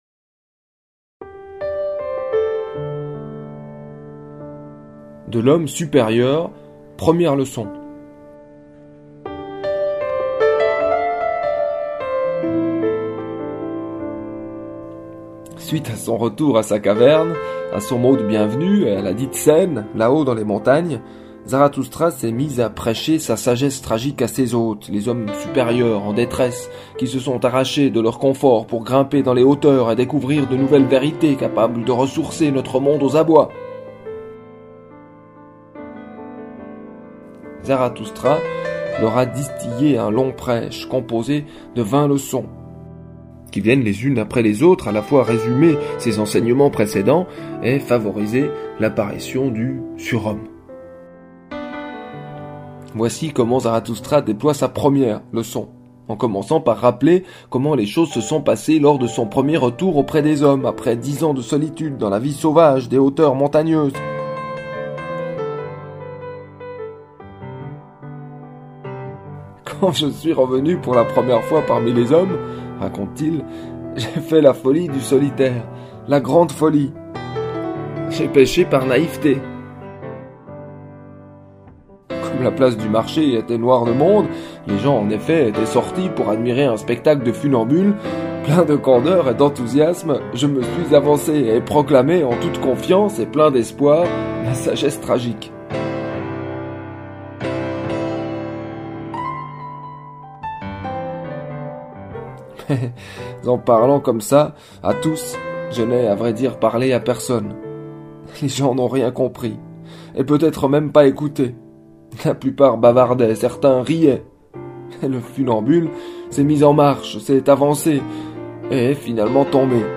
Musique : Keith Jarrett, Köln Concert, 1975.